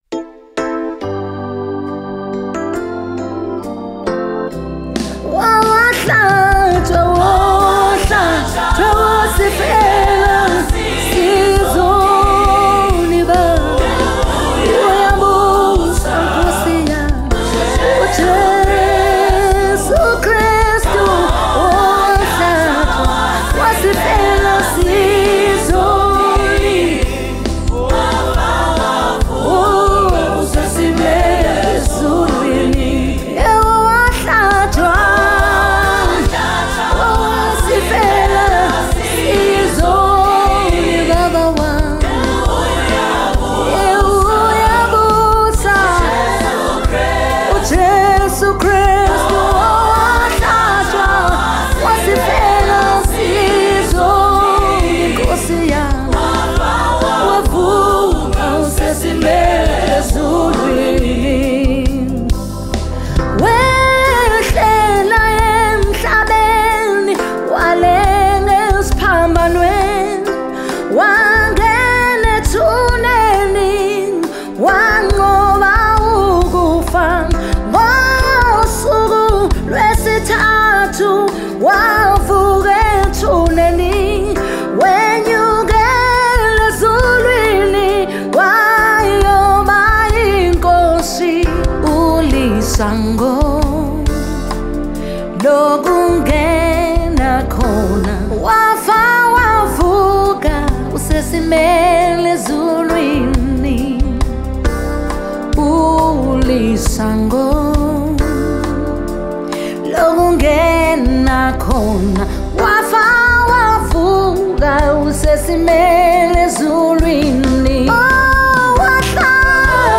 February 4, 2025 Publisher 01 Gospel 0